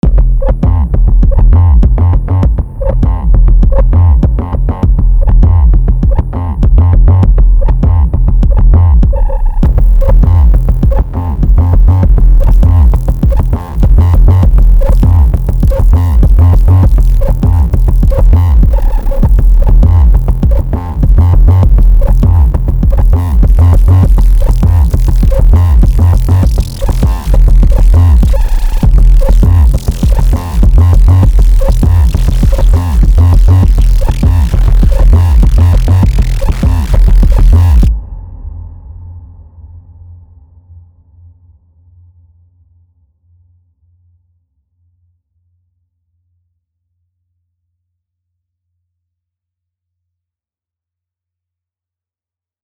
Still taming the double K-2 into Landscape Stereo Field. Trying another signal path (2K2 both clear and routed through the LSF, so its crispy but not burnt).
Not quite perfect but loving the little tails when there are pauses.